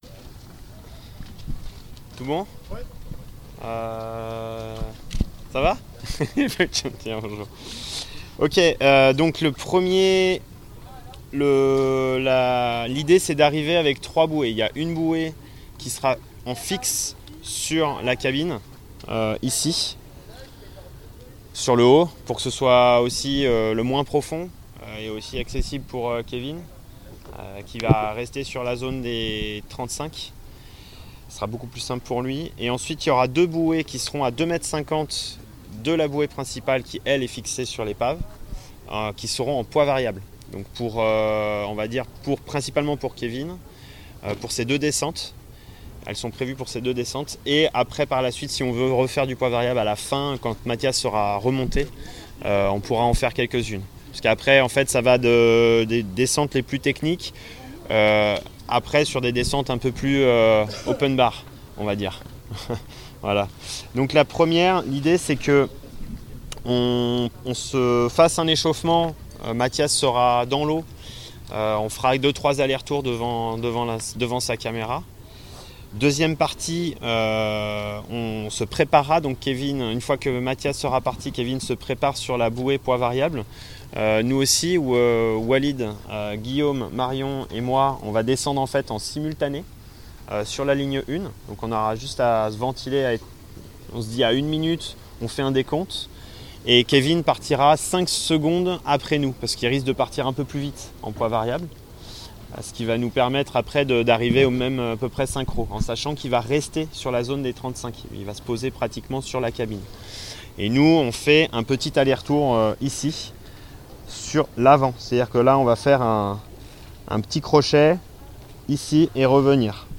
Reportage film avec des plongeurs en apnée sur le France